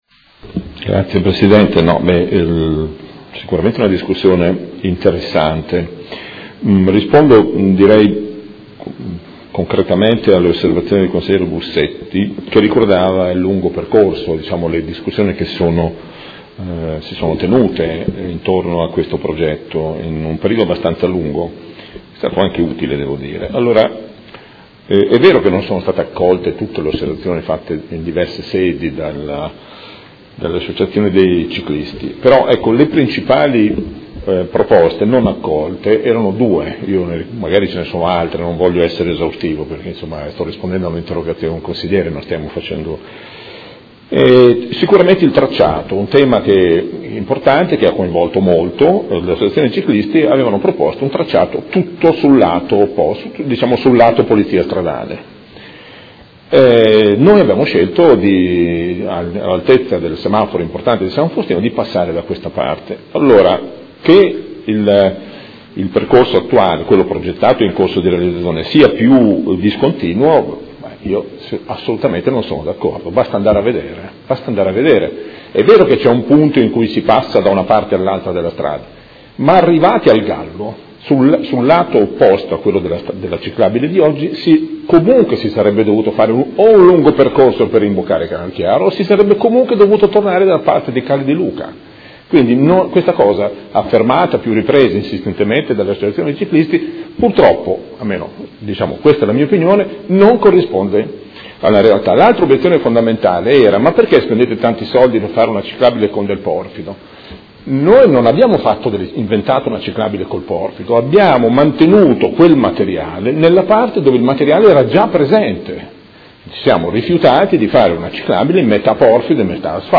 Seduta del 31/03/2016. Interrogazione del Gruppo Consiliare Forza Italia avente per oggetto: Restringimento della carreggiata di Via Pietro Giardini.